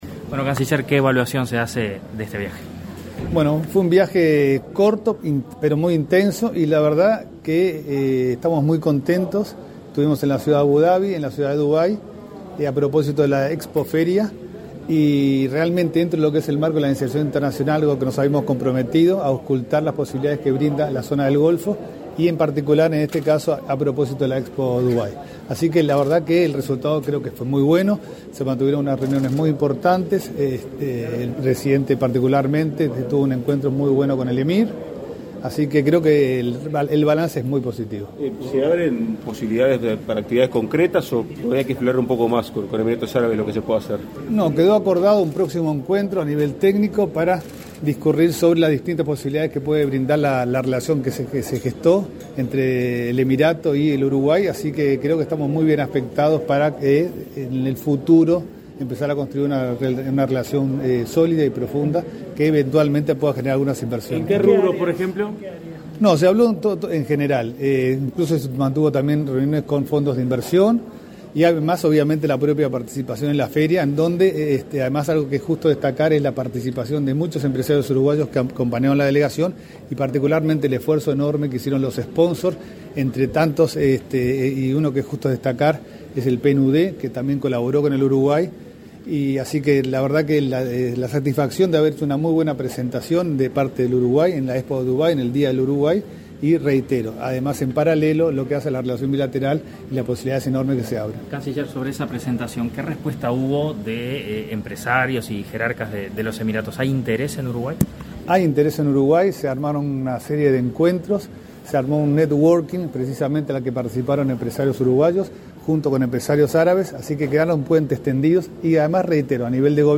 Declaraciones a la prensa del ministro de Relaciones Exteriores, Francisco Bustillo
El canciller Bustillo, quien integró la misión de Uruguay en Emiratos Árabes, efectuó declaraciones a la prensa.